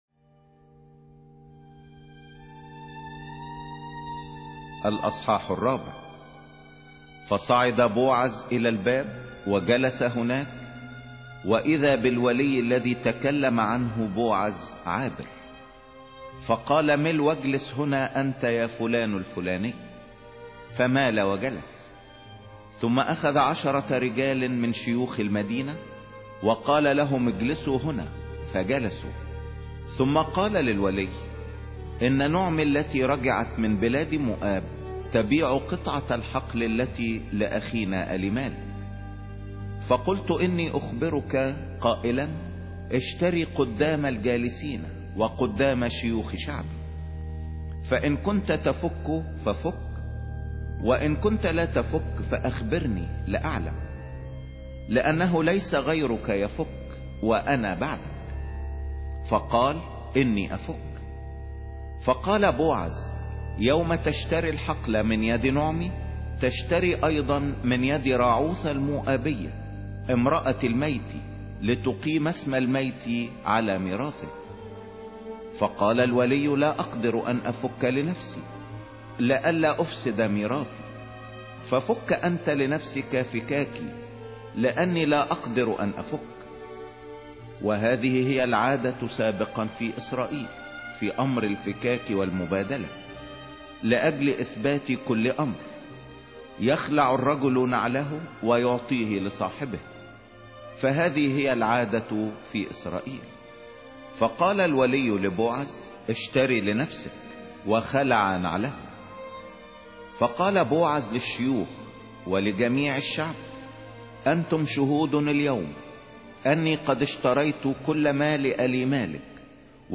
سفر راعوث 04 مسموع